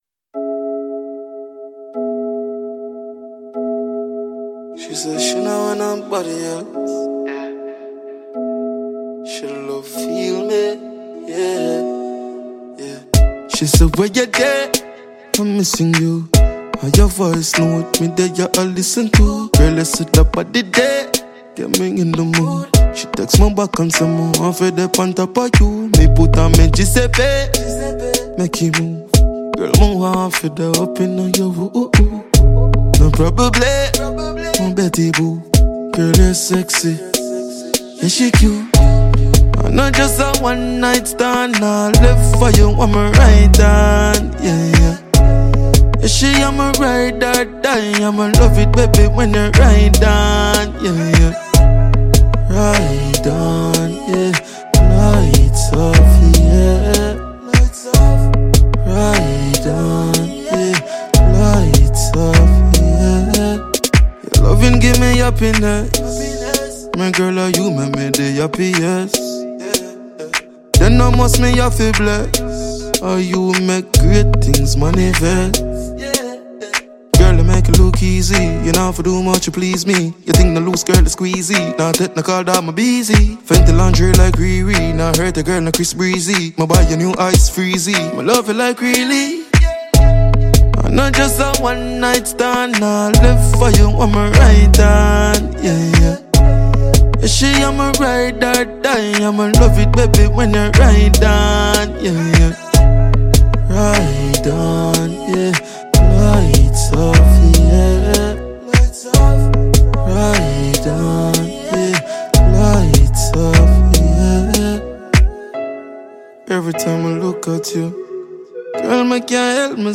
Dancehall/HiphopMusic
Jamaican award-winning dancehall artiste